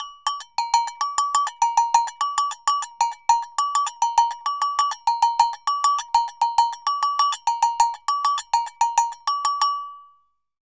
Agogo_Samba_100_2.wav